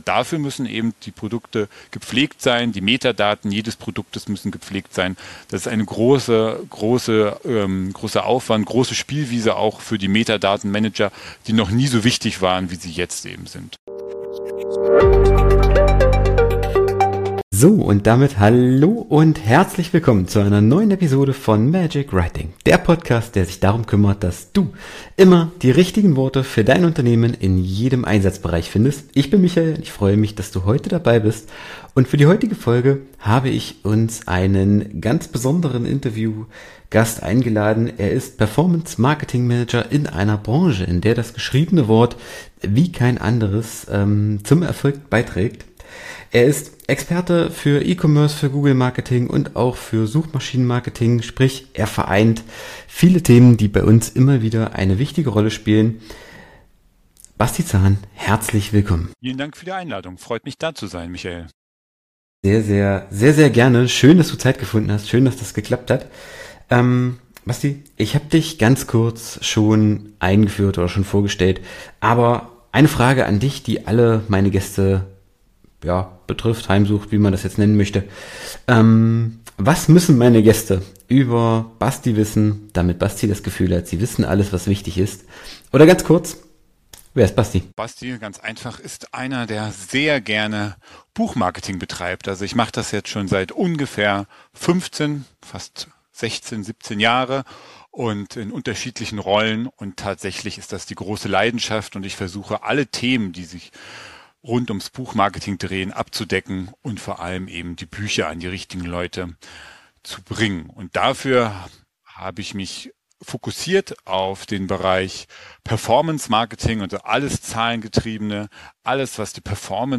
Dieses Interview richtet sich an Entscheidungsträger und Marketingverantwortliche im E-Commerce, die innovative Ansätze zur Steigerung von Sichtbarkeit und Verkaufszahlen suchen.